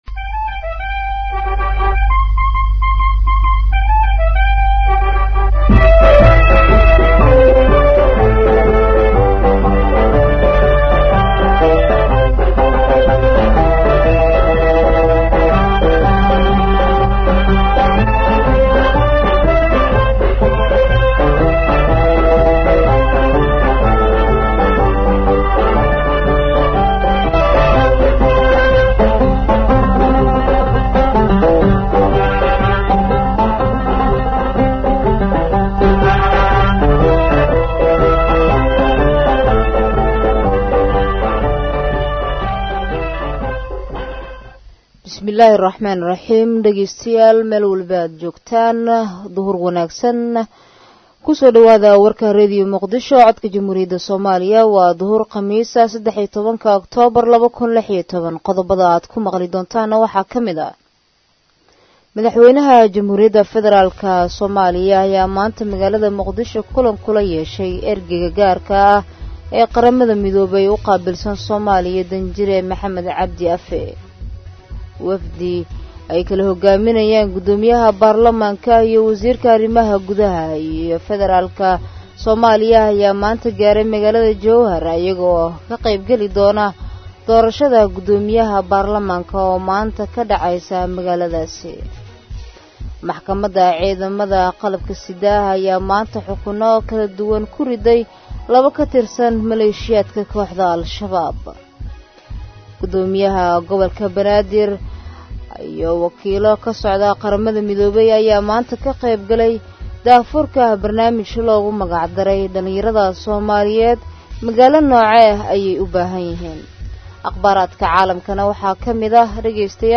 Dhageyso Warka Duhur ee Radio Muqdisho